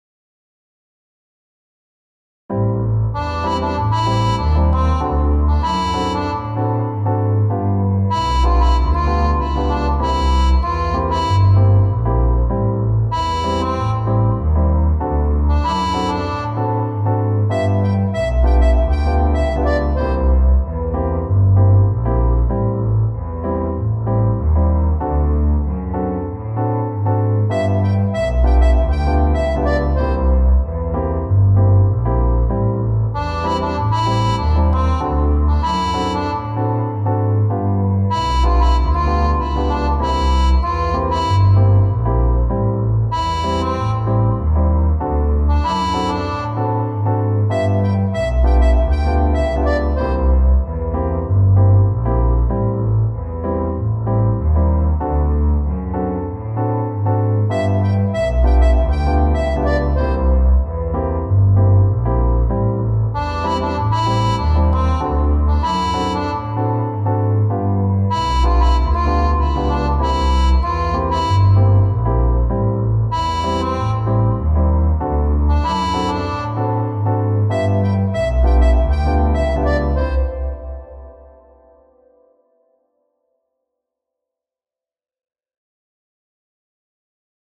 BGM
ジャズ明るい穏やか